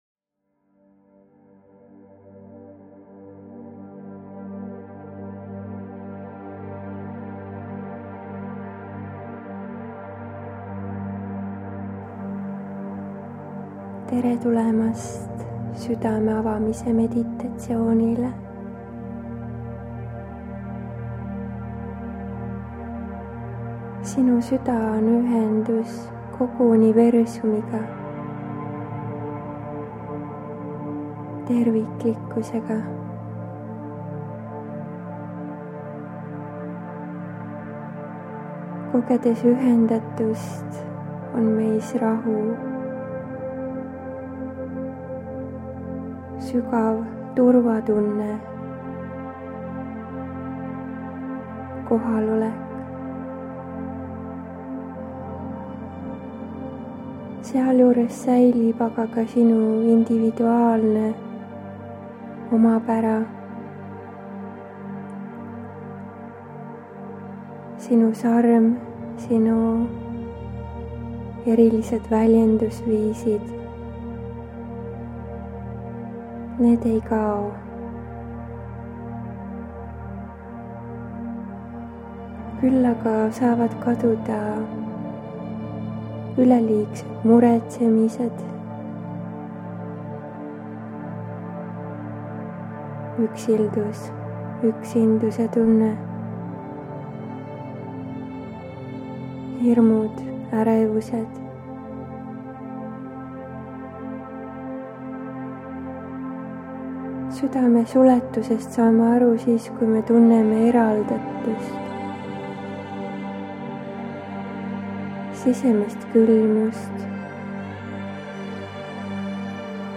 Võid seansi ajal minuga koos taotlusi lausuda või jääda ka lihtsalt rahusse, lõdvestuse ja lubada energiatel läbi enda voolata. Iga kord seda meditatsiooni kuulates saavad puhastatud Su meel, teadvus, keha ja süda.